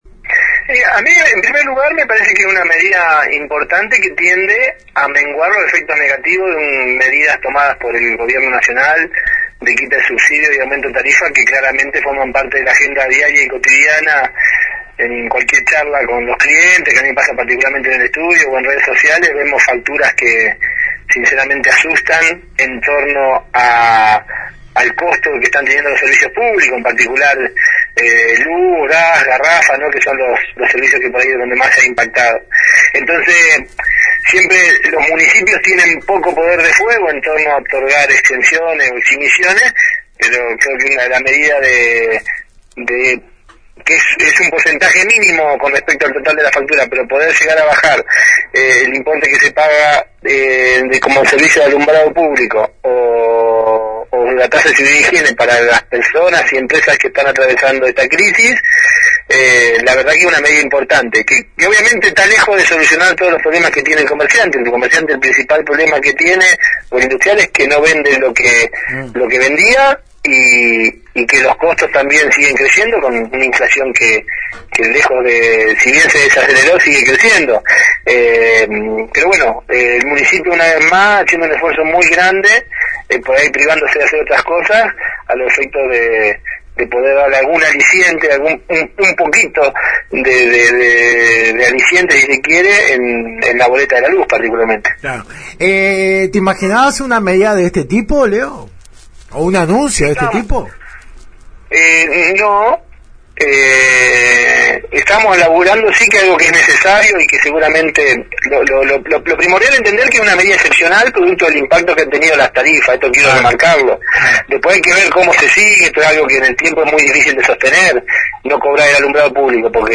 El concejal por el oficialismo se refirió este martes en la 91.5 a las medidas anunciadas por el intendente Alberto Gelené el pasado viernes. Entre ellas, el no cobro hasta fin de año de la Tasa por Alumbrado Público y Seguridad e Higiene a comercios e industrias en situación de crisis.